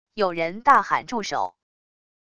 有人大喊住手wav音频